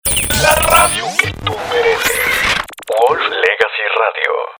NUESTRAS VOCES PARA CREAR SPOTS PARA RADIO O PERIFONEO